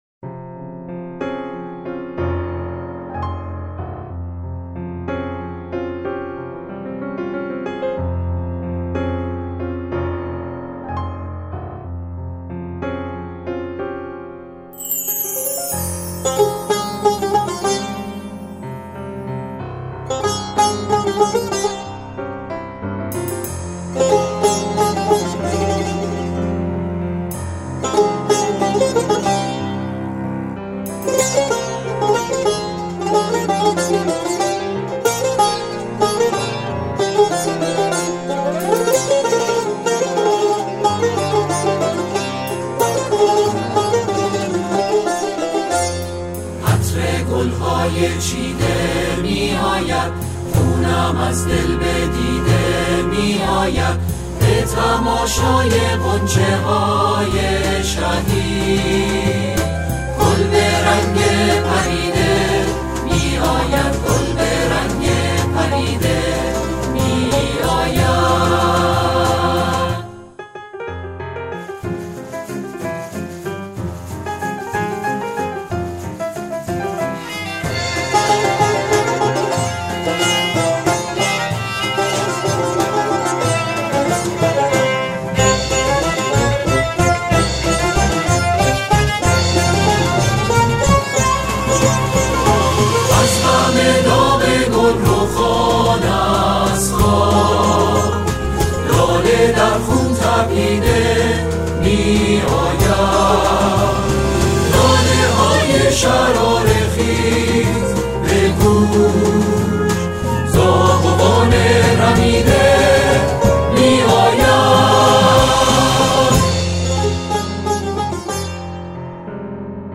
سرودهای شهدا
اعضای گروه کر اجرا می‌کنند.